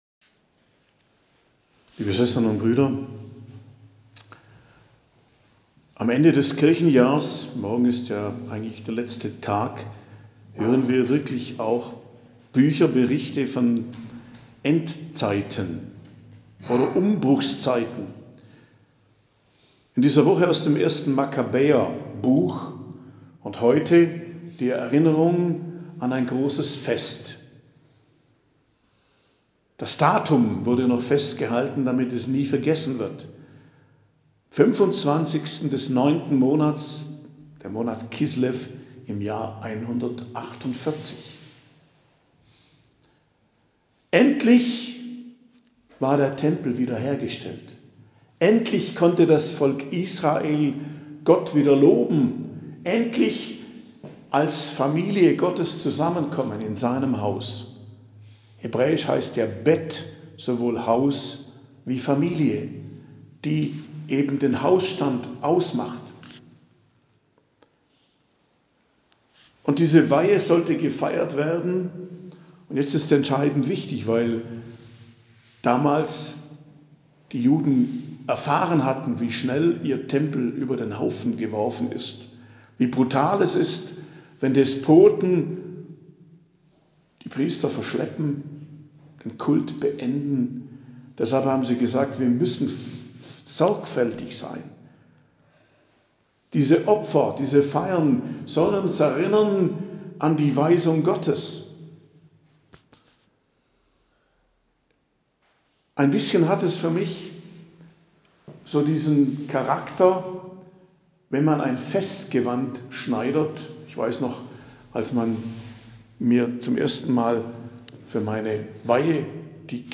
Predigt am Freitag der 33. Woche i.J., 24.11.2023